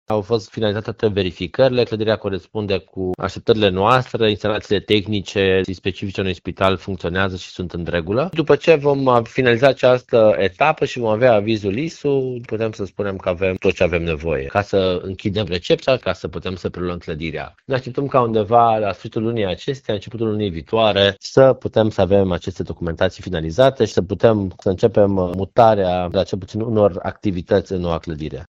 Potrivit viceprimarului Ruben Lațcău, avizul este așteptat în următoarele săptămâni.